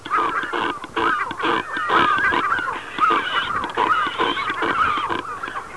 Kormoran (Phalacrocorax carbo)
Stimme: am Brutplatz: »chro chro«, »krao«.
cormoran[1].au